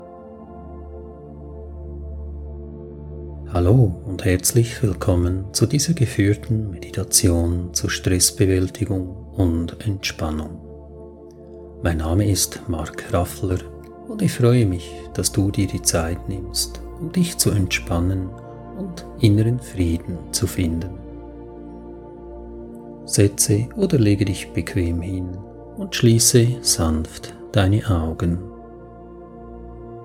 Erlebe tiefe Entspannung und reduziere Stress mit dieser geführten Meditation zur Stressbewältigung.